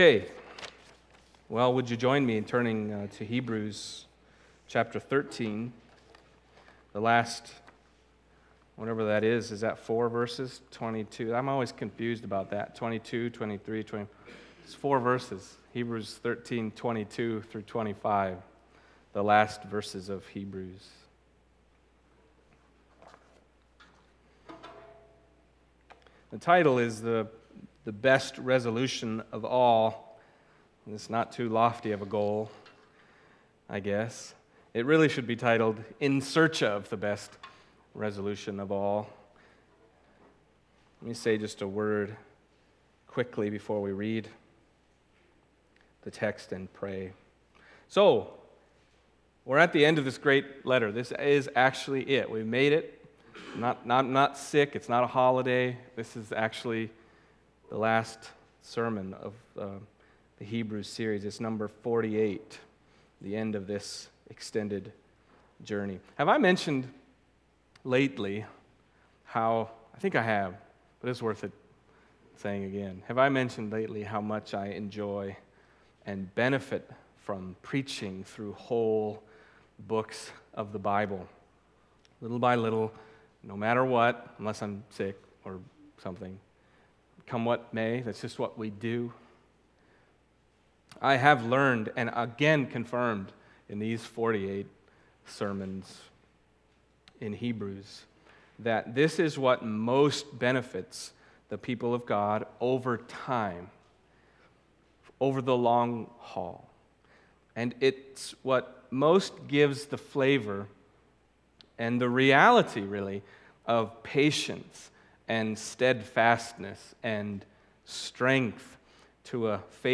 Passage: Hebrews 13:22-25 Service Type: Sunday Morning